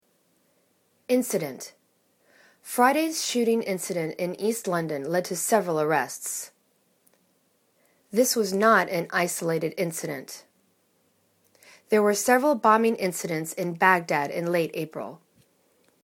in.ci.dent  /'insidәnt/ n